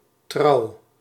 Trouw (Dutch pronunciation: [trʌu]
Nl-trouw.ogg.mp3